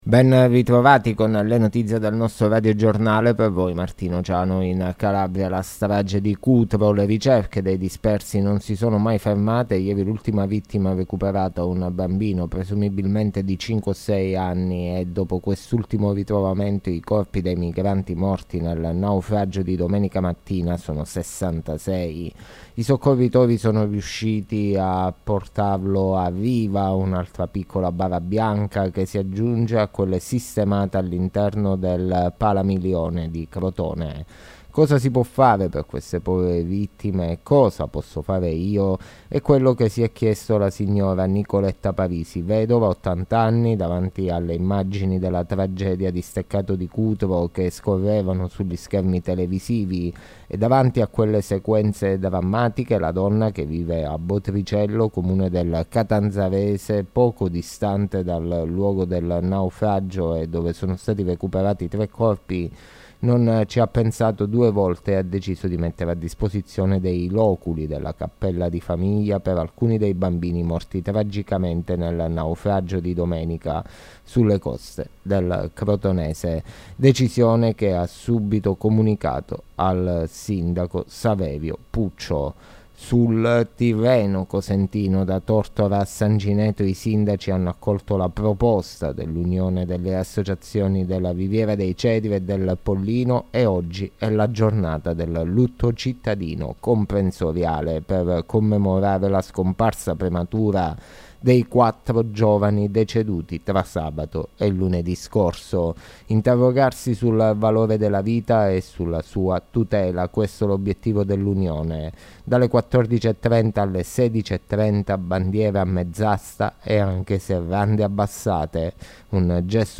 LE NOTIZIE DEL GIORNO DI MERCOLEDì 01 MARZO 2023